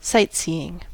Ääntäminen
Ääntäminen US Tuntematon aksentti: IPA : /ˈsaɪt.ˌsiː.ɪŋ/ Haettu sana löytyi näillä lähdekielillä: englanti Käännöksiä ei löytynyt valitulle kohdekielelle.